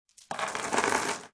Descarga de Sonidos mp3 Gratis: cadena caida.